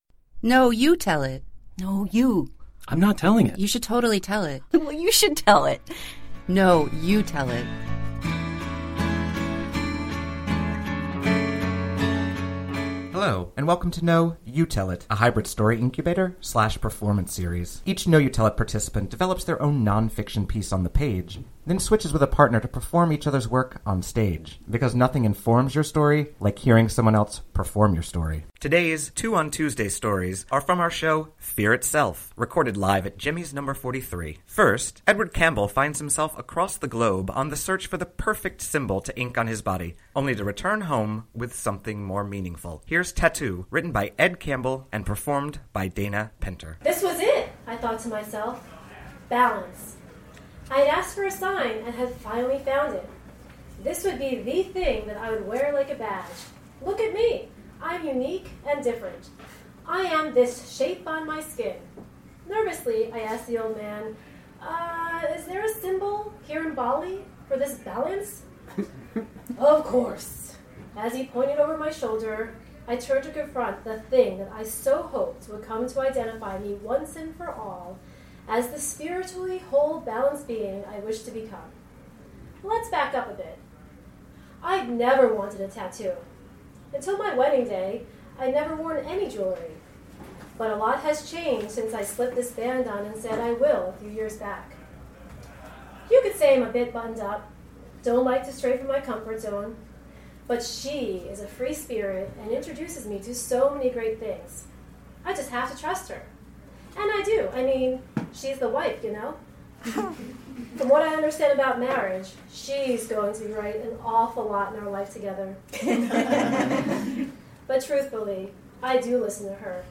Today’s “Two on Tuesday” story swap is from our ‘Fear Itself’ show